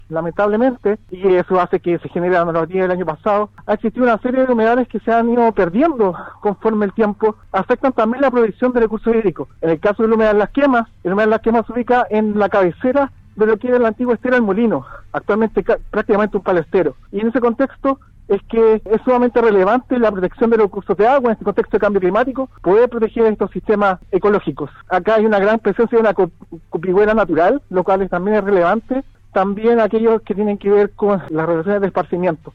en entrevista con Radio Sago